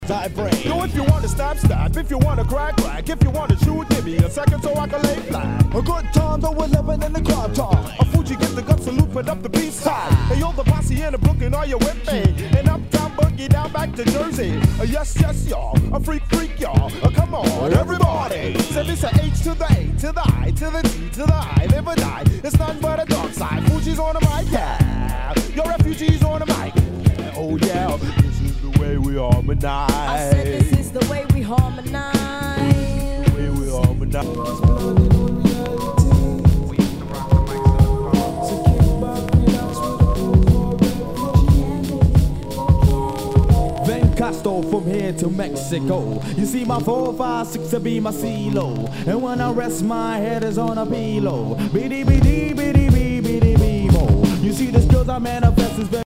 HIPHOP/R&B
全体にチリノイズが入ります